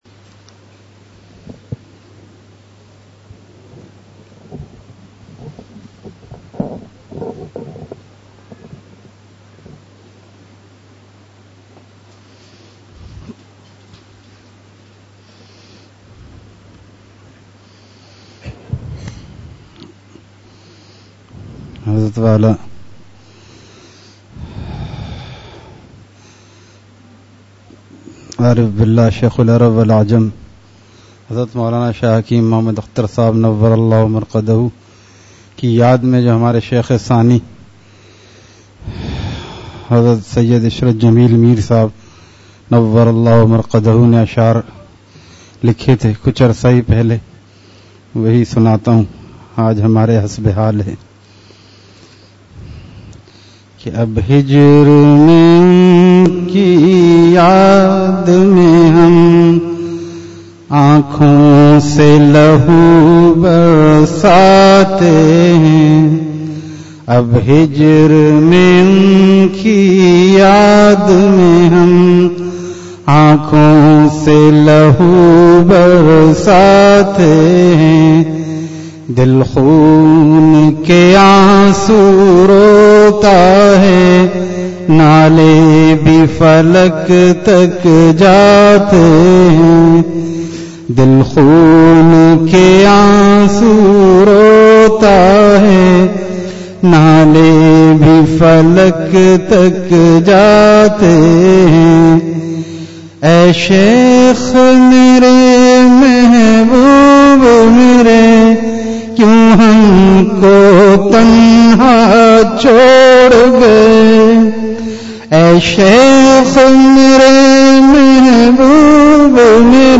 Please download the file: audio/mpeg مجلس محفوظ کیجئے اصلاحی مجلس کی جھلکیاں آہ!